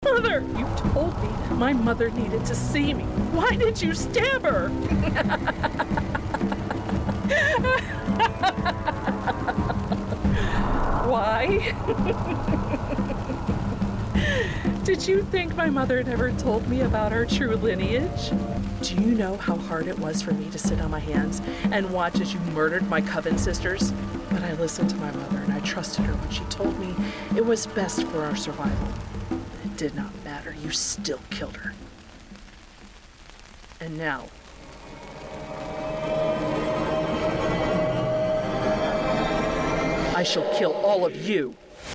Character - Gladys
Witch.mp3